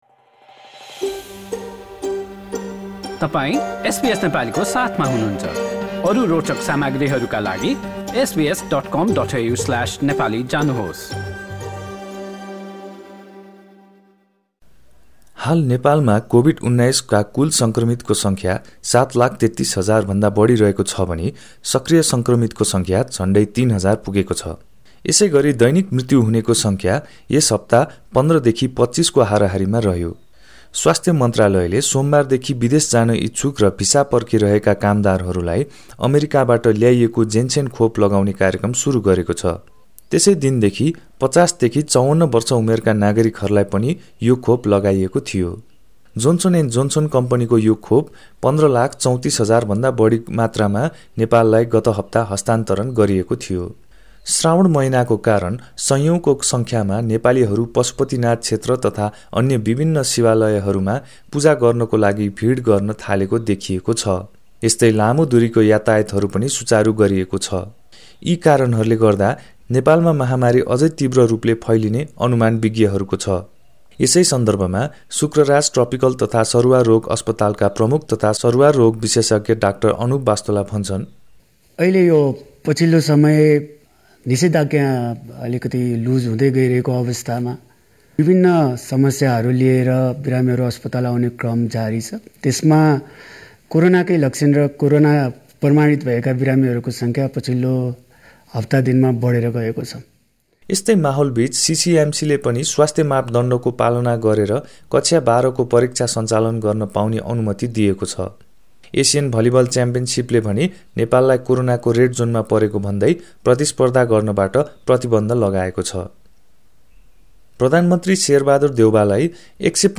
A text version of this news report is available in the Nepali language.